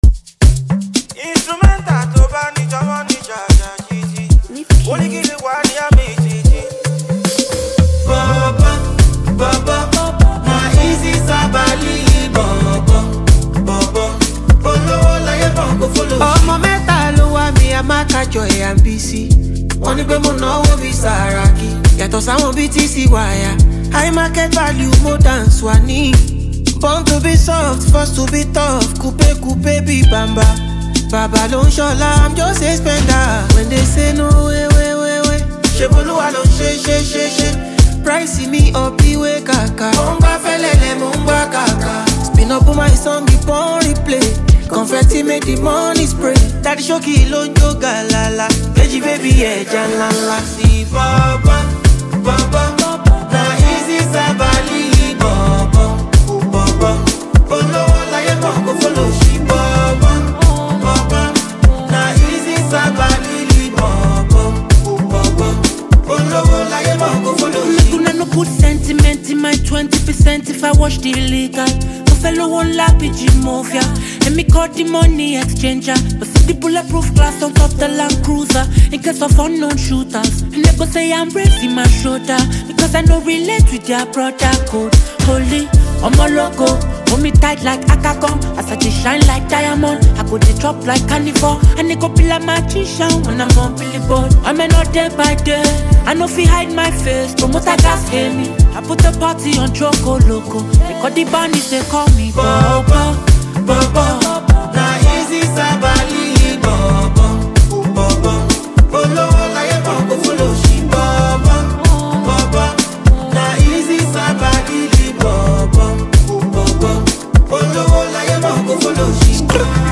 Nigerian Afrobeats